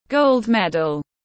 Huy chương vàng tiếng anh gọi là gold medal, phiên âm tiếng anh đọc là /ˌɡəʊld ˈmed.əl/